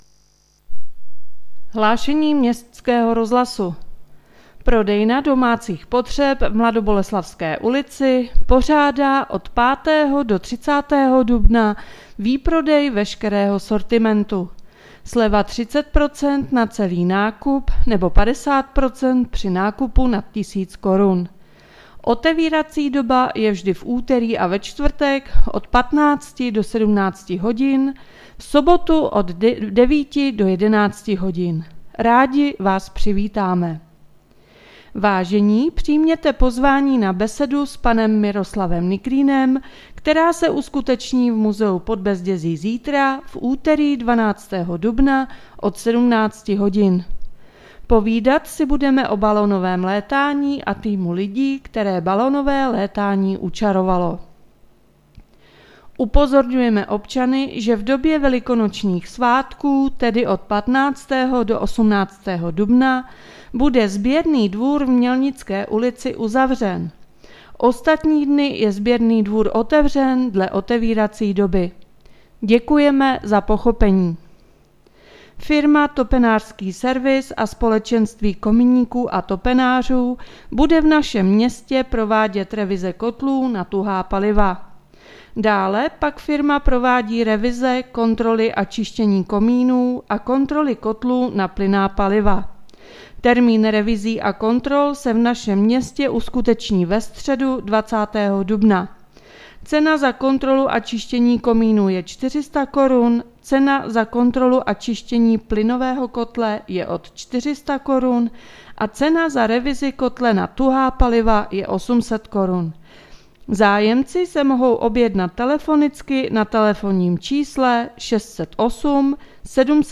Hlášení městského rozhlasu 11.4.2022